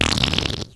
sound_laser_alt.ogg